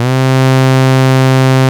STRS C2 F.wav